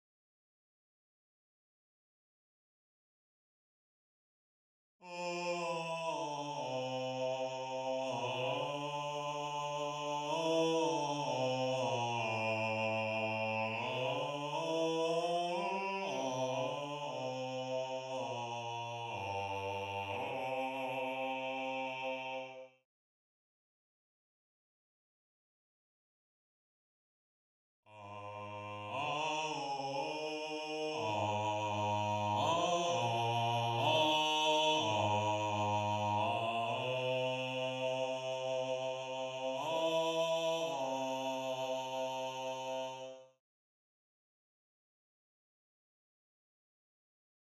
Voice 7 (Bass/Bass)
gallon-v8sp5-23-Bass_0.mp3